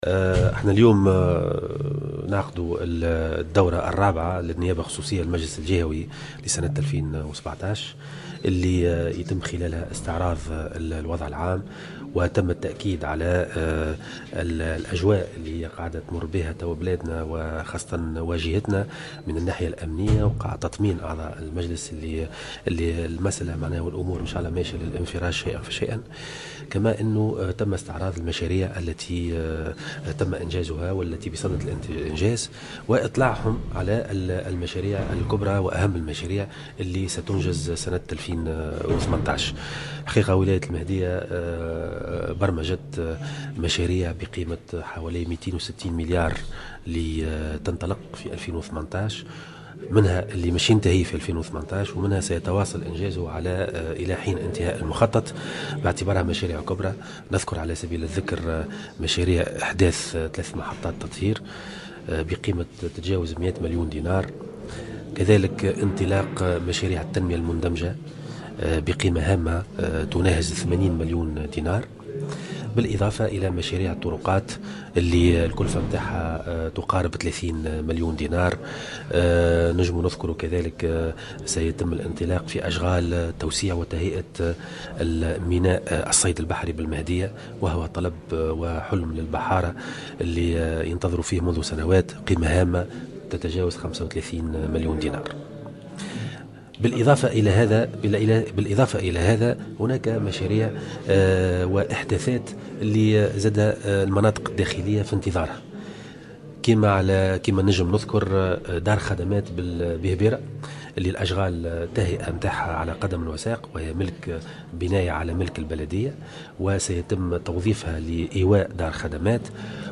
وقال والي المهدية، في تصريح لمراسل الجوهرة أف أم، عقب الاجتماع، إن الولاية برمجت مشاريع بقيمة 260 مليون دينار، بعضها ستنتهي أشغالها خلال السنة الحالية تزامنا مع انطلاق اشغال مشاريع أخرى.